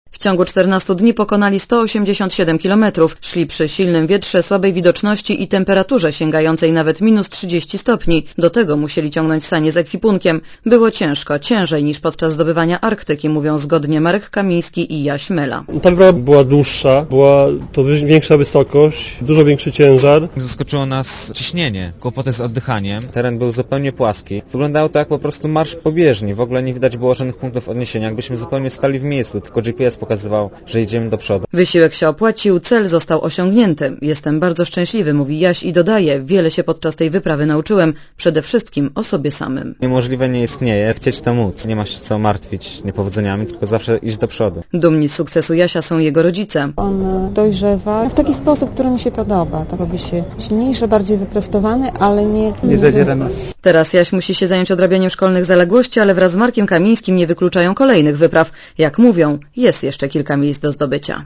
W piątek, tuz po powrocie z wyprawy z bieguna południowego polarnicy dzieliła się wrażeniami.
Komentarz audio W piątek, tuz po powrocie z wyprawy z bieguna południowego polarnicy dzieliła się wrażeniami.